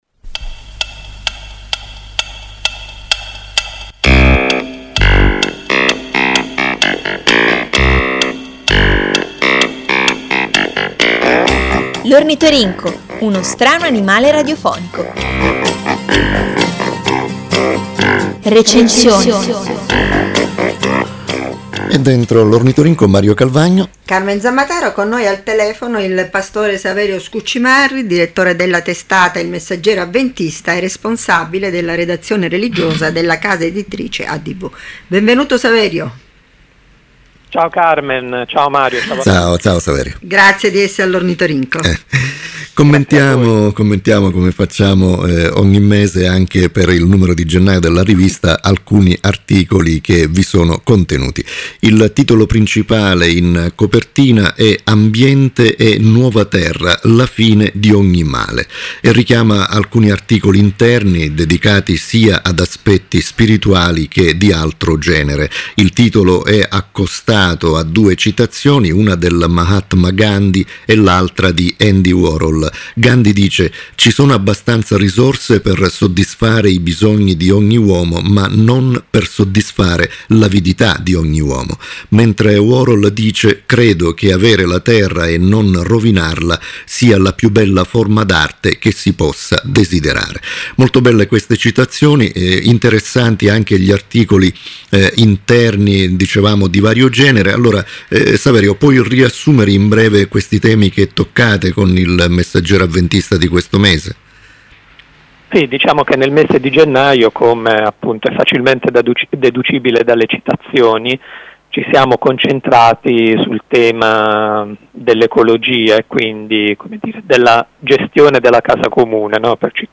per commentare i principali articoli Ascolta l’intervista.